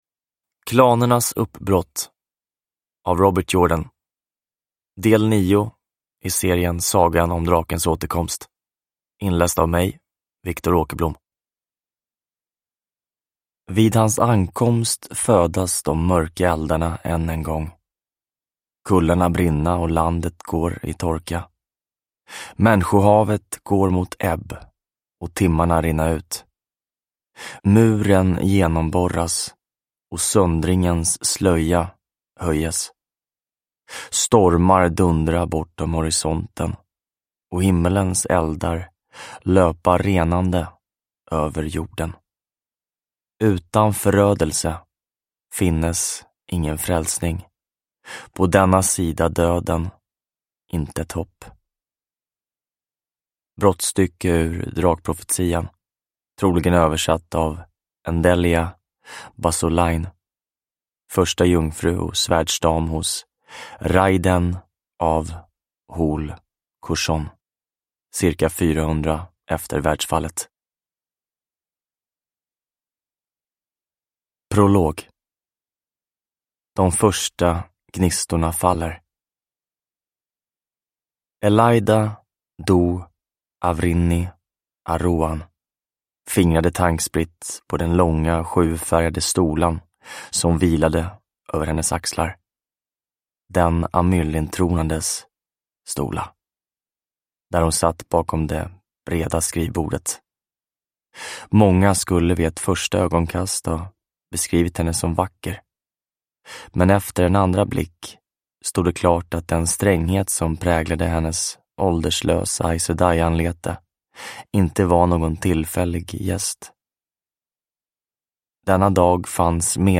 Klanernas uppbrott – Ljudbok – Laddas ner